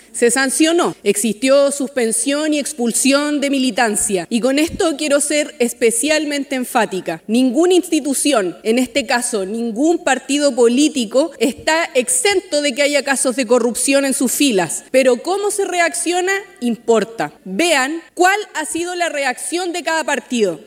El caso ProCultura, sin dudas, ha agudizado la tensión entre oficialismo y oposición, lo que quedó demostrado la tarde de este lunes, cuando se llevó a cabo una tensa sesión especial en la Sala de la Cámara de Diputados y Diputadas, que terminó con la acusación de una supuesta agresión entre parlamentarios y múltiples dardos cruzados.